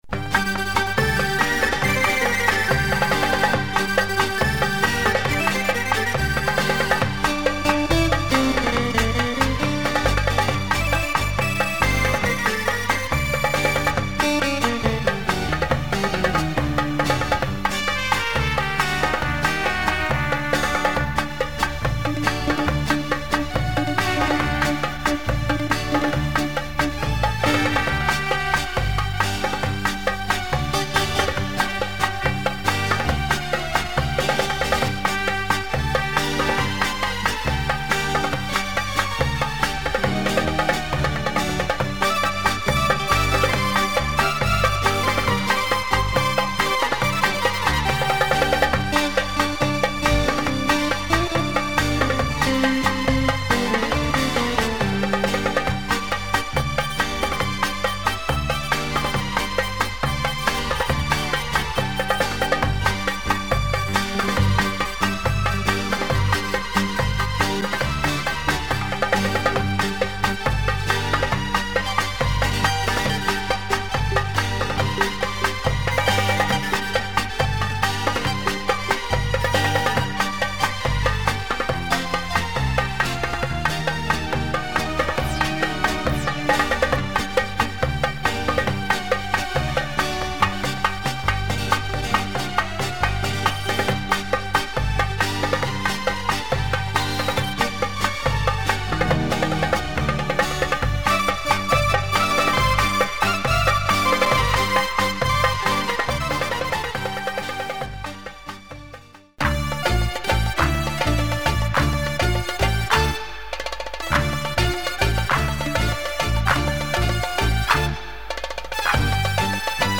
80’s Lebanese pop beats